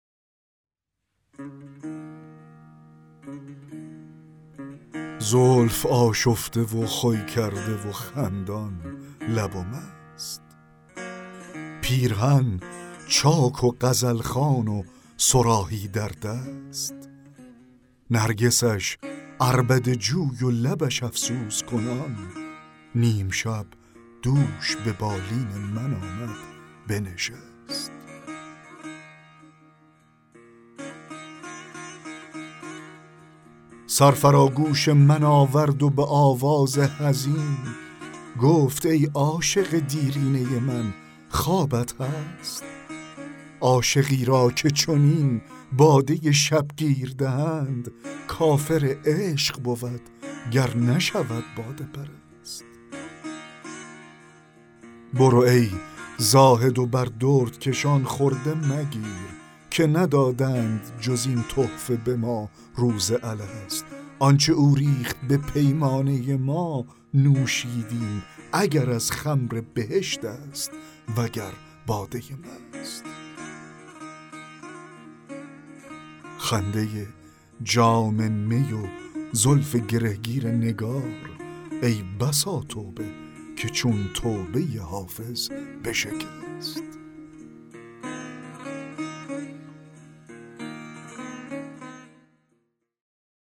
دکلمه غزل 26 حافظ
دکلمه غزل زلف آشفته و خوی کرده و خندان لب و مست